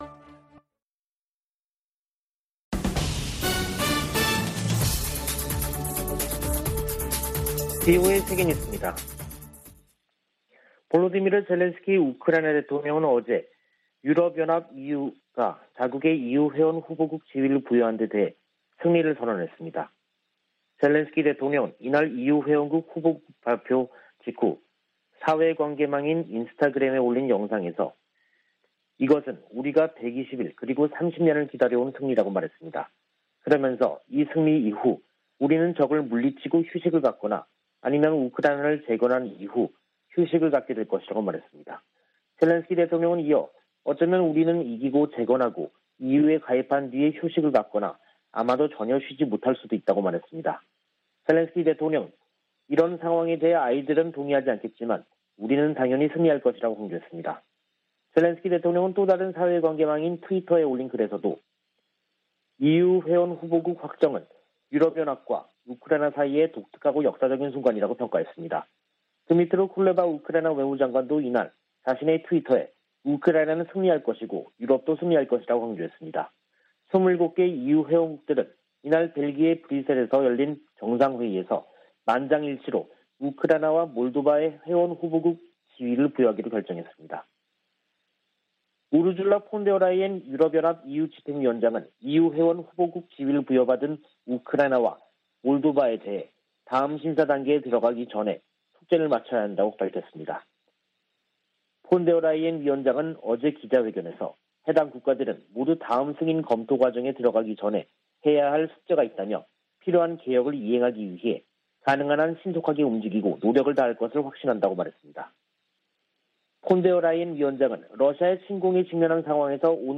VOA 한국어 간판 뉴스 프로그램 '뉴스 투데이', 2022년 6월 24일 2부 방송입니다. 미 하원 군사위 의결 국방수권법안(NDAA)에 한국에 대한 미국의 확장억제 실행 방안 구체화를 요구하는 수정안이 포함됐습니다. 미국의 군사 전문가들은 북한이 최전선에 전술핵을 배치한다고 해도 정치적 의미가 클 것이라고 지적했습니다. 북한은 노동당 중앙군사위원회 확대회의에서 전쟁억제력 강화를 위한 중대 문제를 심의 승인했다고 밝혔습니다.